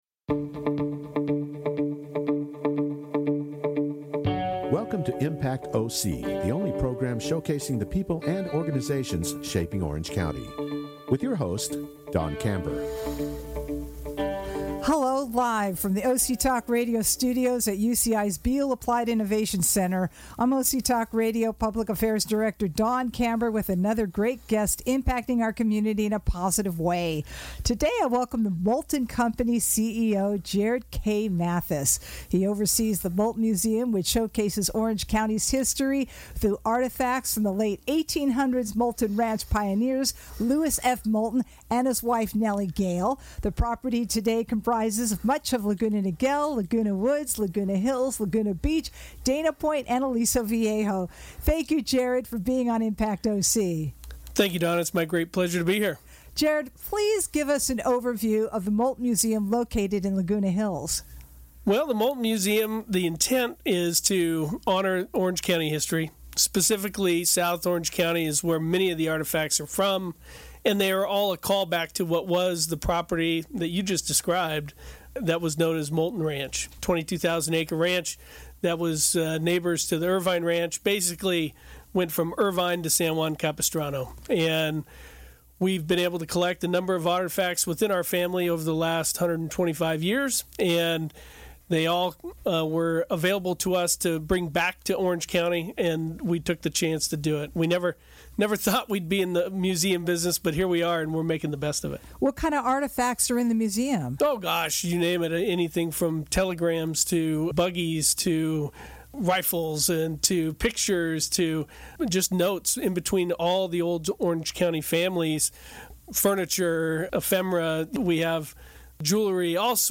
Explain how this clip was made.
Only on OC Talk Radio, Orange County’s Only Community Radio Station which streams live from the University of California-Irvine’s BEALL APPLIED INNOVATION CENTER.